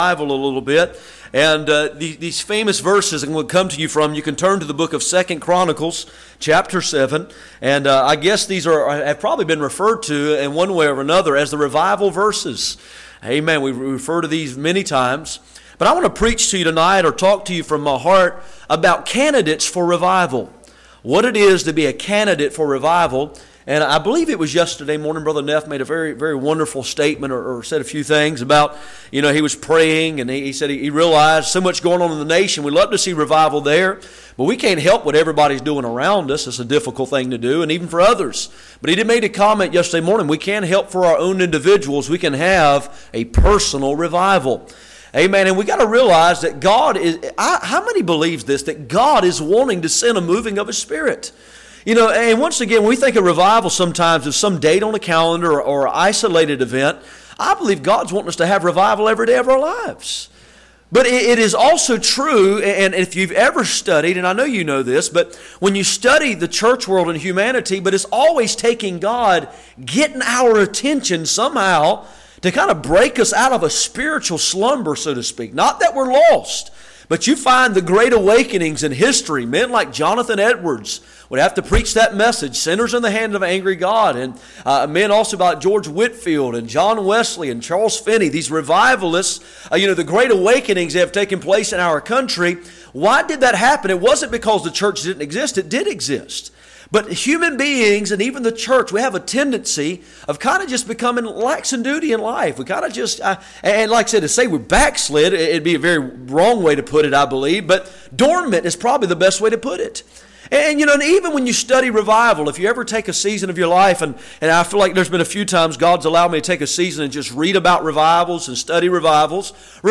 None Passage: 2 Chronicles 7:12-15 Service Type: Sunday Evening %todo_render% « Why do the righteous suffer?